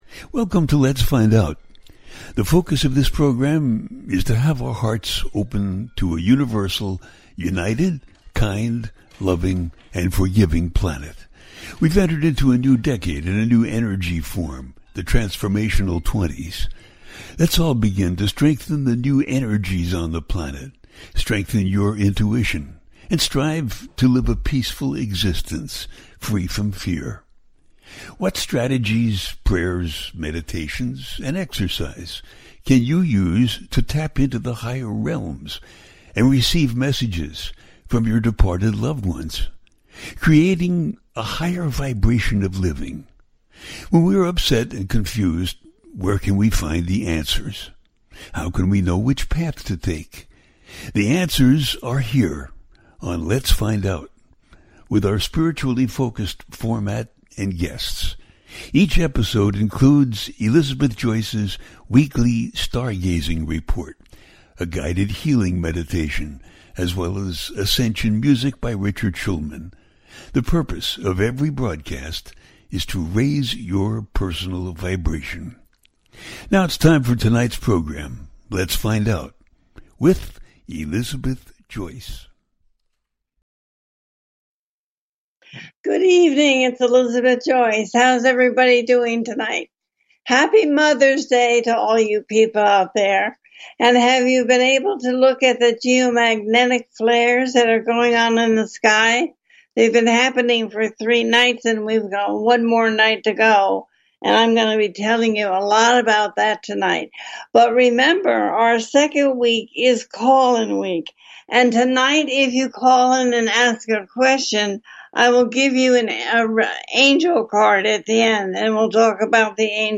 Talk Show Episode
A teaching show.
The listener can call in to ask a question on the air.
Each show ends with a guided meditation.